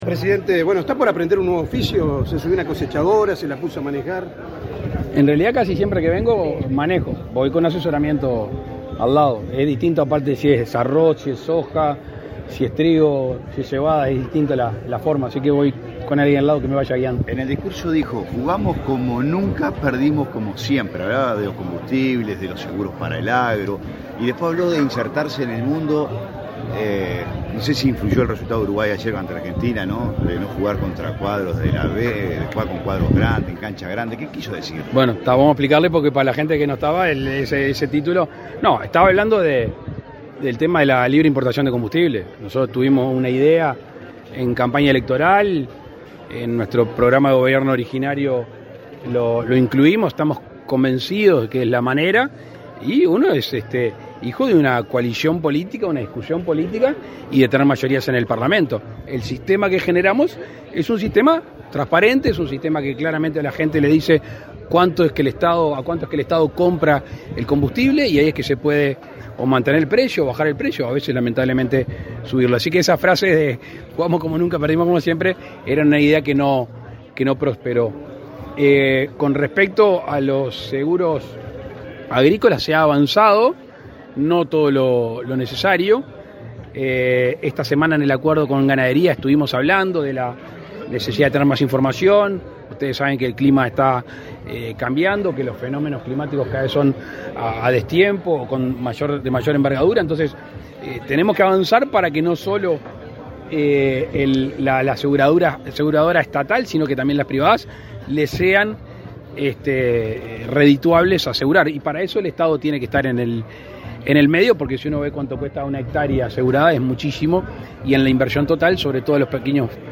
Declaraciones a la prensa del presidente de la República, Luis Lacalle Pou
Declaraciones a la prensa del presidente de la República, Luis Lacalle Pou 17/11/2023 Compartir Facebook X Copiar enlace WhatsApp LinkedIn Tras participar en la inauguración de la cosecha de trigo, en la ciudad de Dolores, este 17 de noviembre, el presidente de la República, Luis Lacalle Pou, realizó declaraciones a la prensa.
Lacalle prensa.mp3